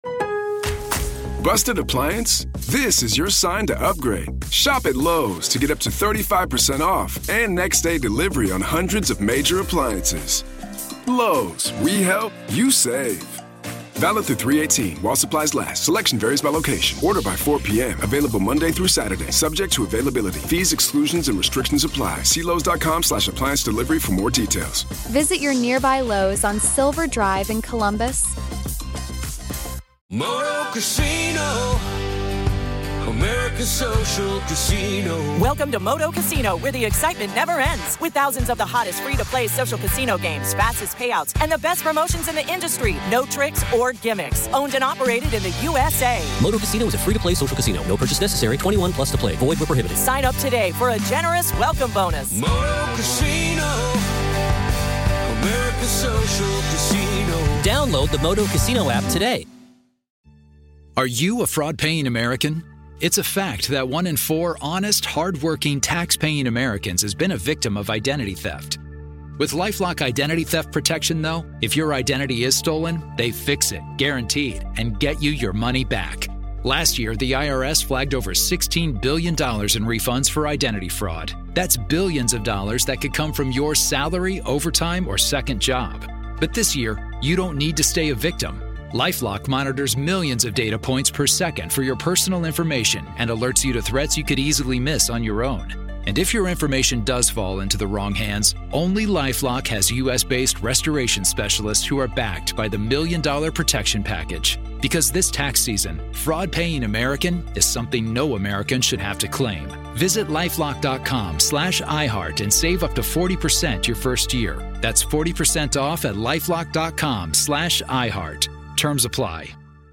Today, Part One of our conversation